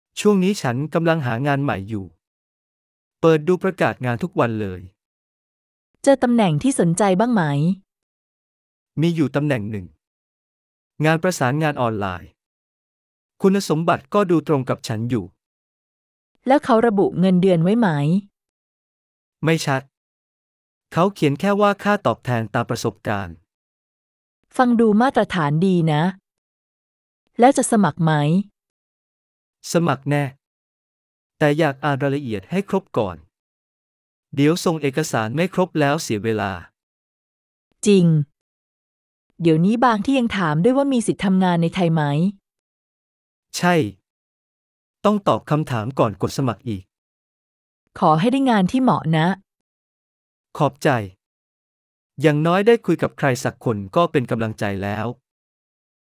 A natural conversation about job listings, qualifications, compensation, and the stress of online applications.
week-1-recap-1-looking-for-work.mp3